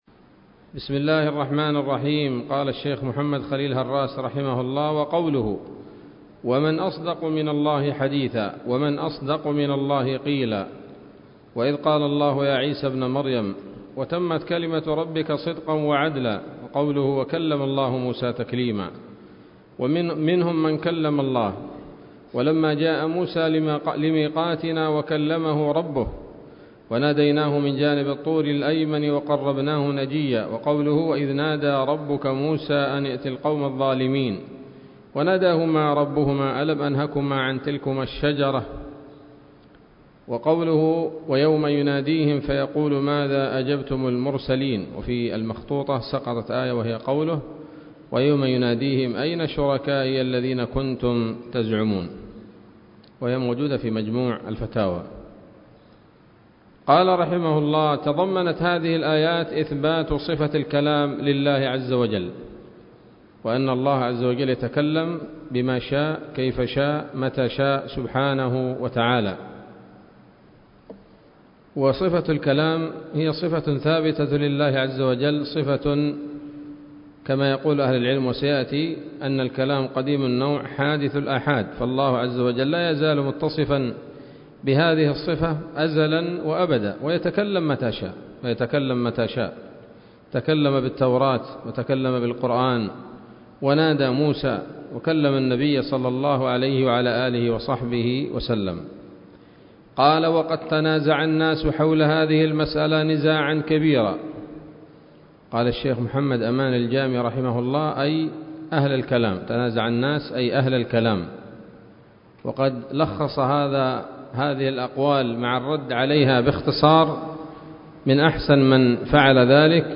الدرس الثامن والسبعون من شرح العقيدة الواسطية للهراس